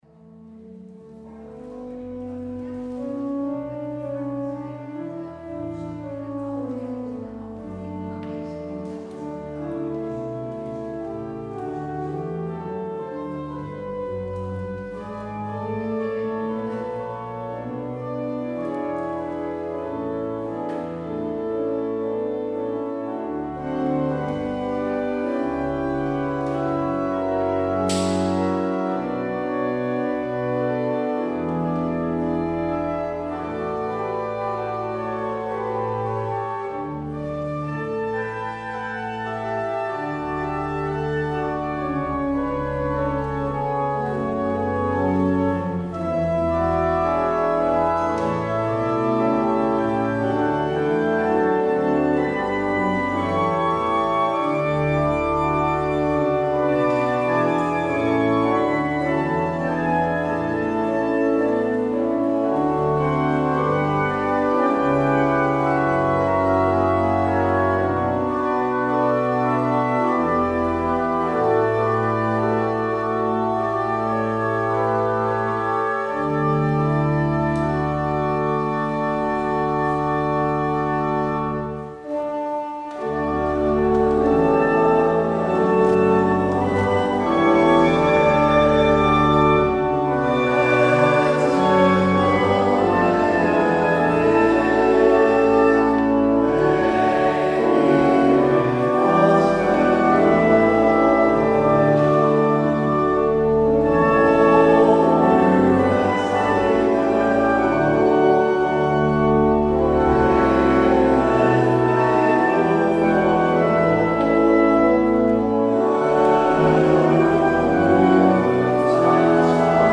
Daarom zenden een herhaling uit van een dienst uit augustus 2022.
Luister deze kerkdienst hier terug